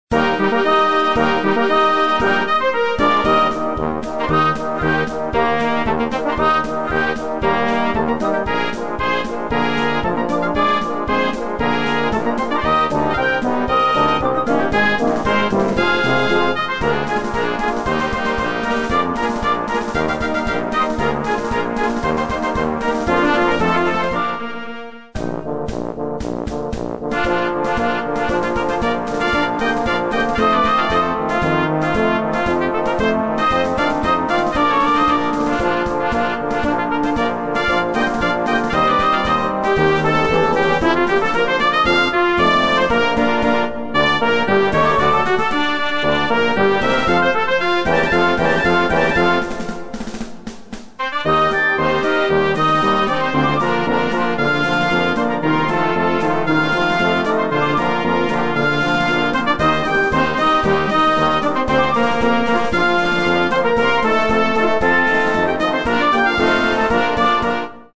Blasmusik:
Märsche: